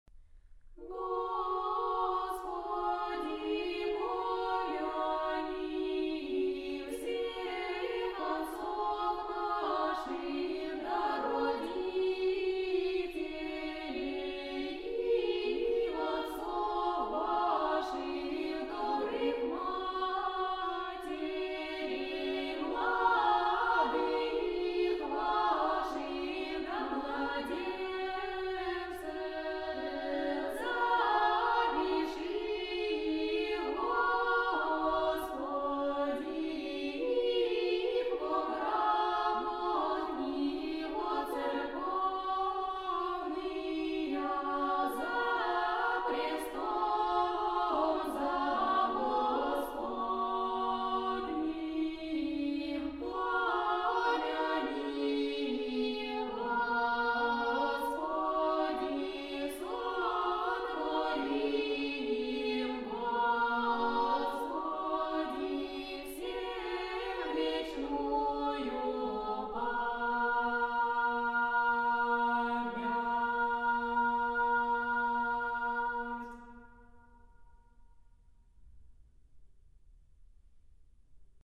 chants017.mp3